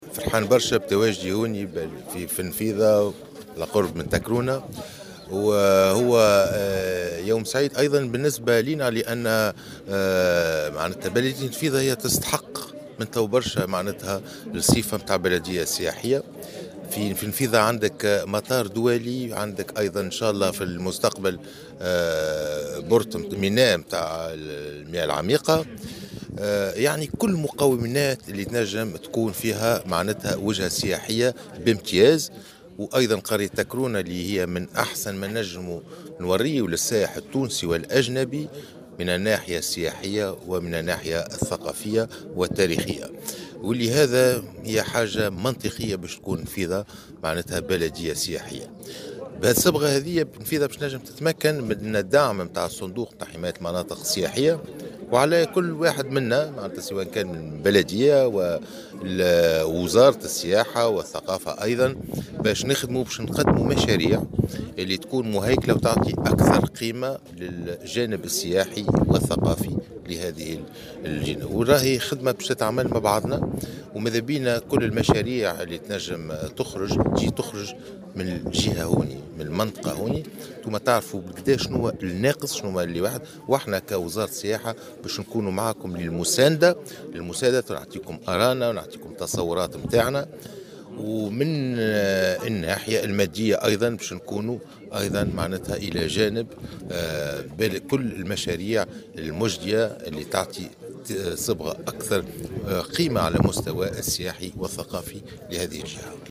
وأبرز الوزير في تصريح للجوهرة أف أم، أن هذا الإعلان سيمكن النفيضة من الحصول على دعم صندوق حماية المناطق السياحية، وتثمين ما تزخر به الجهة من مناطق تاريخية على غرار منطقة "تكرونة" التي بإمكانها أن تصبح وجهة متميزة للسائح التونسي والأجنبي.